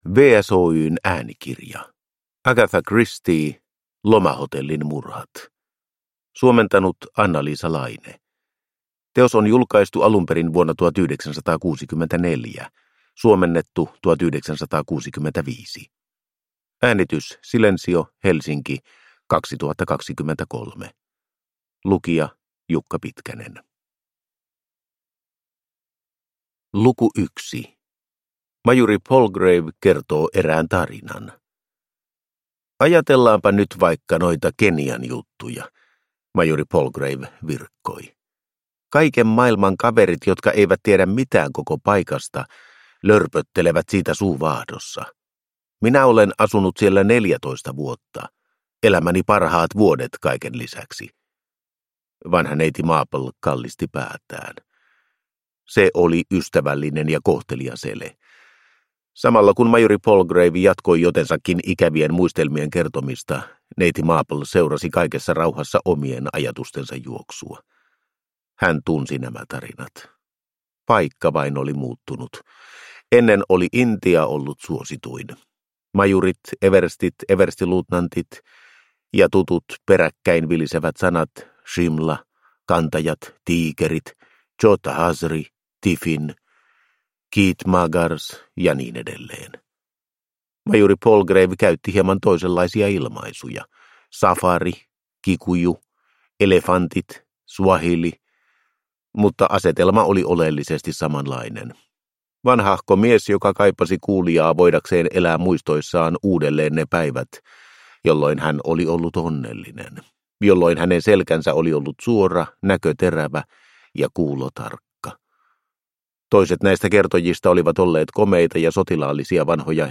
Lomahotellin murhat – Ljudbok – Laddas ner